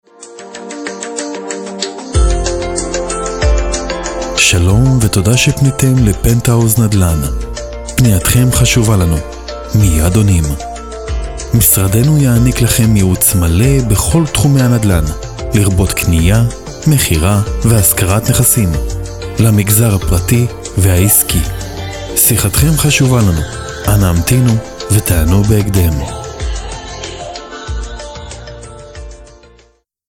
הפקת צליל בהמתנה
גינגל-פון-פנטאוז-נדלן.mp3